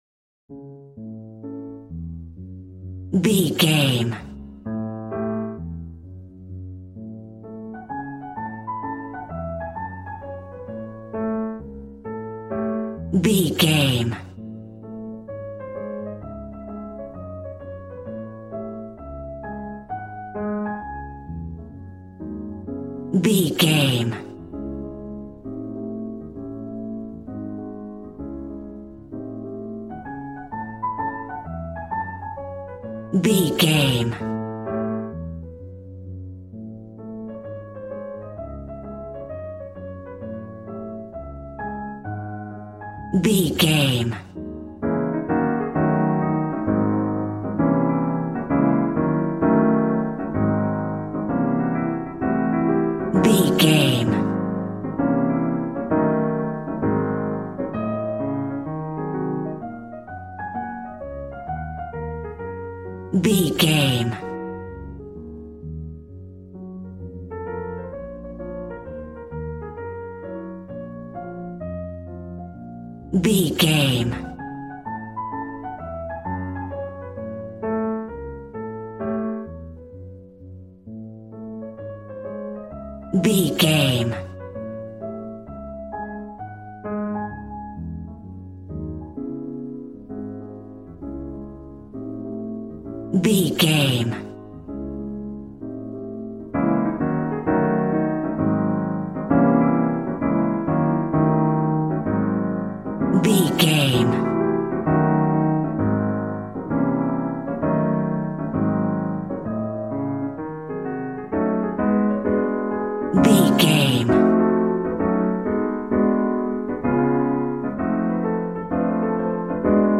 Smooth jazz piano mixed with jazz bass and cool jazz drums.,
Aeolian/Minor
D♭